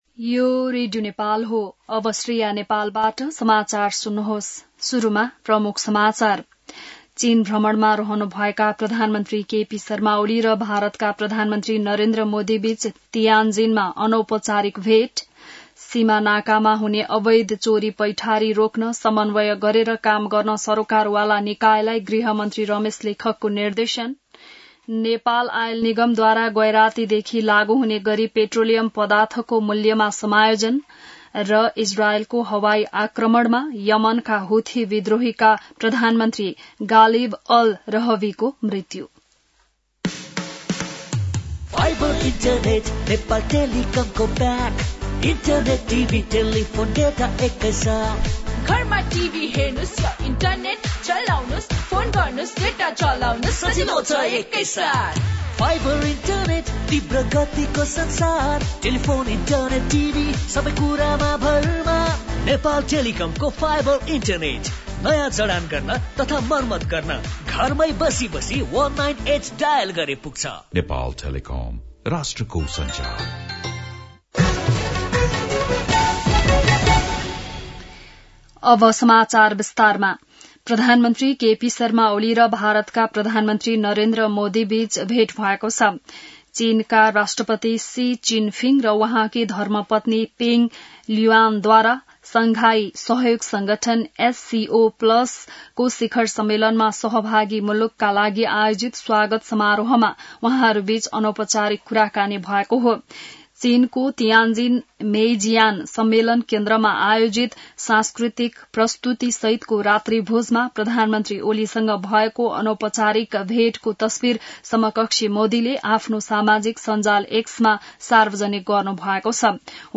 बिहान ७ बजेको नेपाली समाचार : १६ भदौ , २०८२